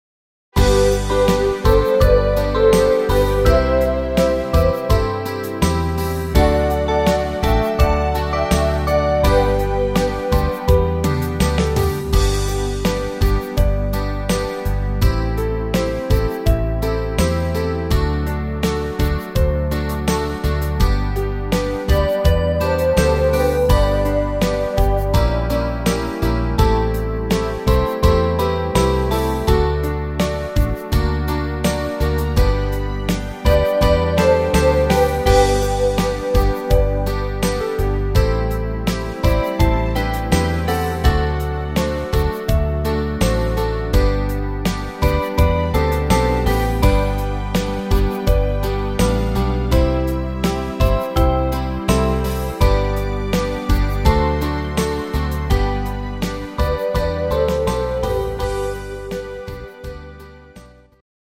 instr. Klarinette